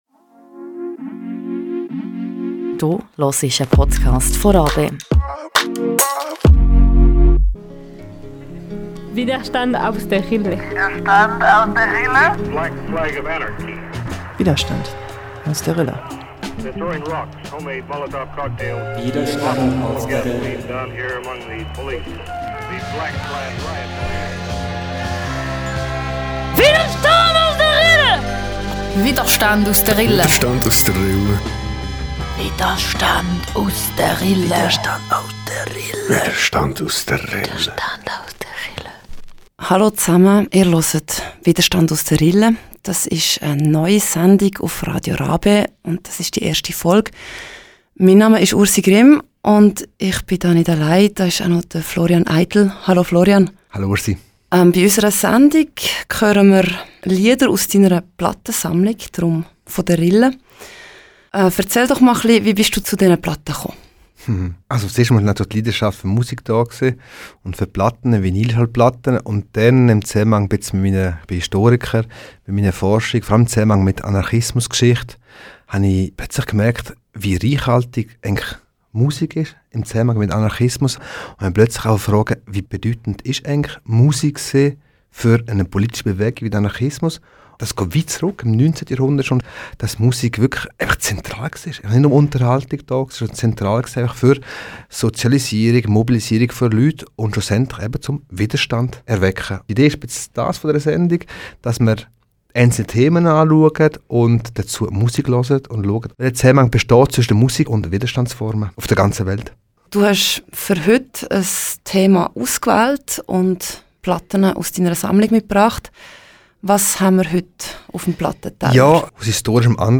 Bei Widerstand aus der Rille widmen wir uns der Musik, die rund um soziale Bewegungen entsteht. In unserer ersten Folge erzählen Lieder aus Kaiseraugst und aus anderen AKW-Besetzungen Geschichten von damals.